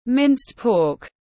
Thịt lợn băm tiếng anh gọi là minced pork, phiên âm tiếng anh đọc là /mɪnst pɔːk/
Minced pork /mɪnst pɔːk/